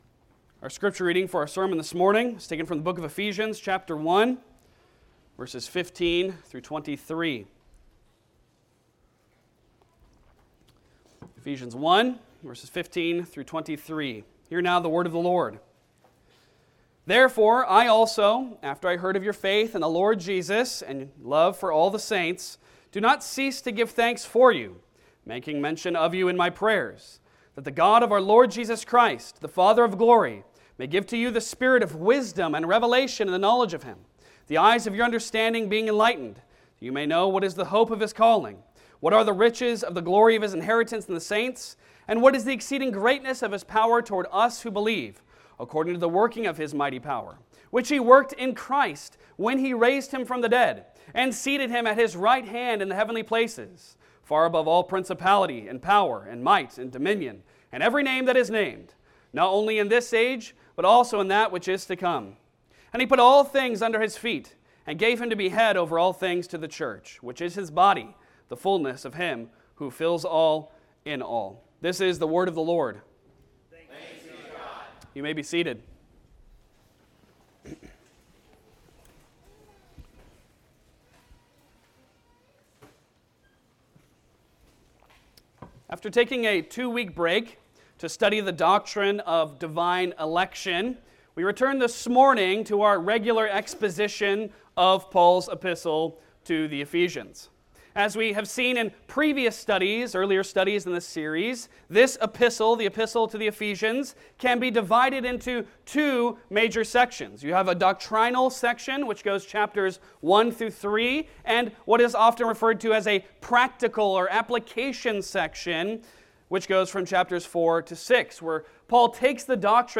Passage: Ephesians 1:15-23 Service Type: Sunday Sermon